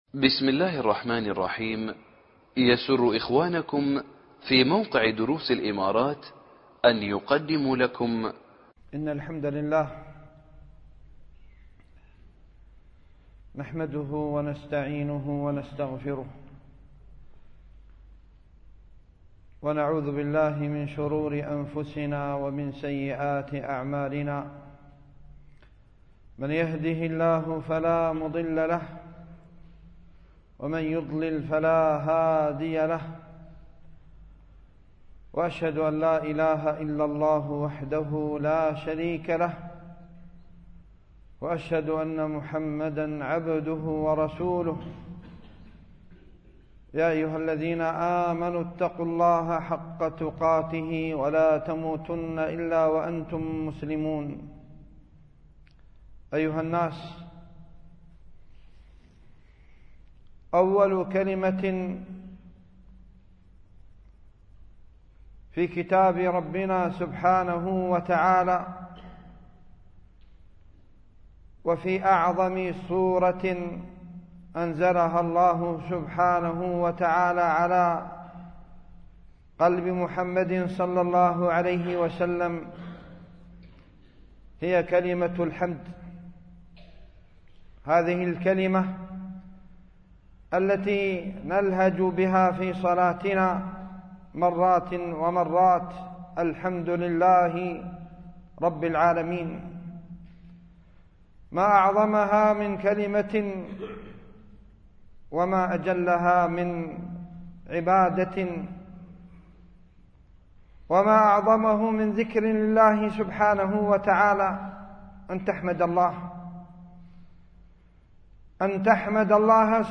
خطبة بعنوان: من سمات الشاب المسلم لـ